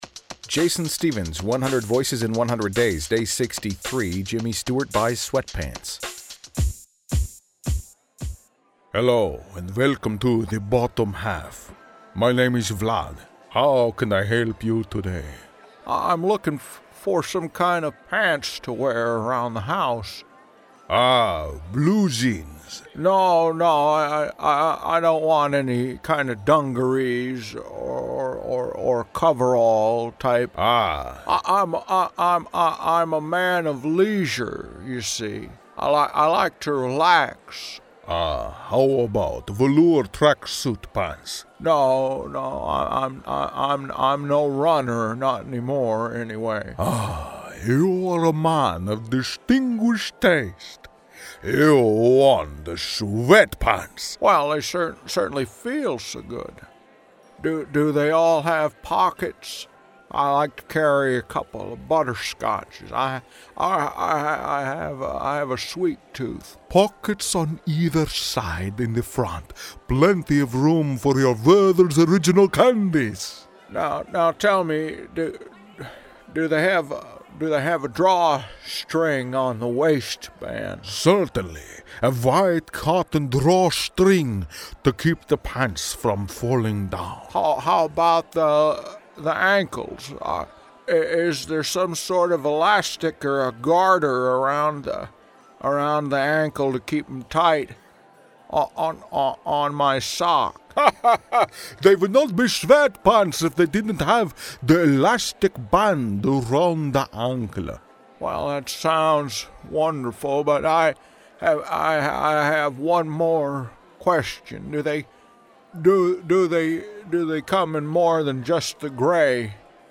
Today’s episode features my Jimmy Stewart impression – and clocking in at 2:47, it is the longest piece yet in the 100 Voices project.
Tags: celebrity voices, Jimmy Stewart impression, sketch comedy impressions